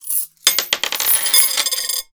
Coins Dropping Sound
household